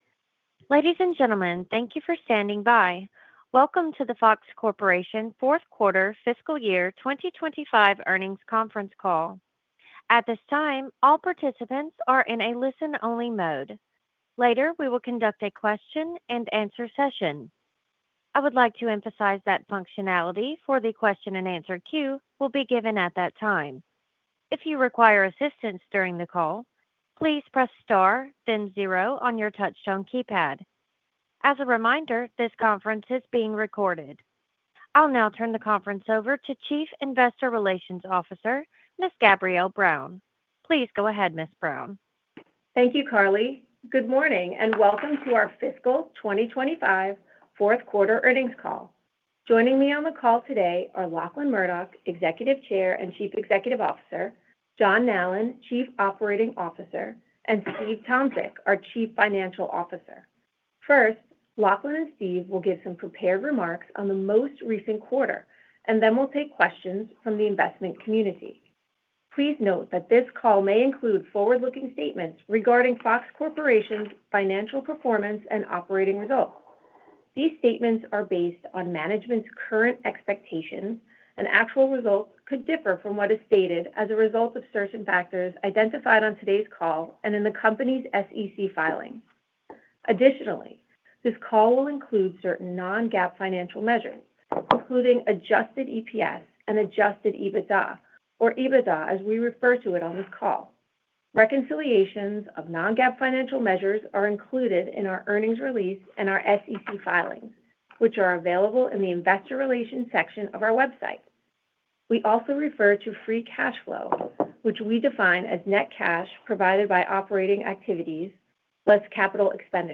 FOX-FY2025-Q4-and-Full-Year-Earnings-Call_Event-Recording.mp3